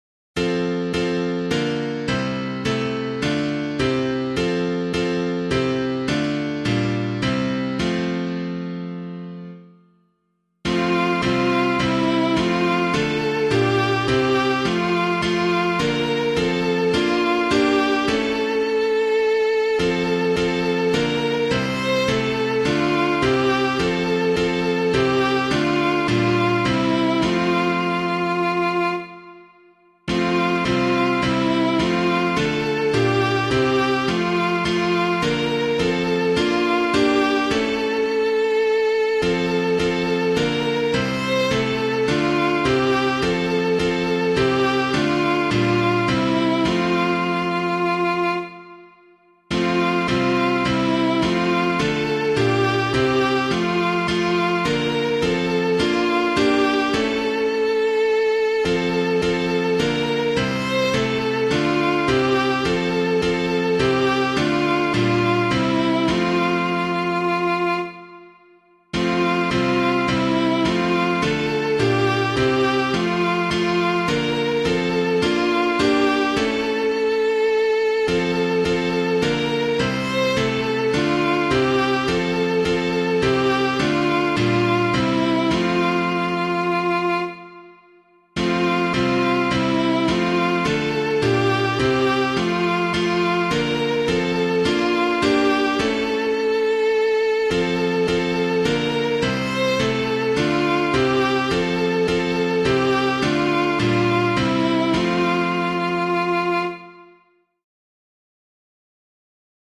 Public domain hymn suitable for Catholic liturgy.
Most Ancient of All Mysteries [Faber - SAINT FLAVIAN] - piano.mp3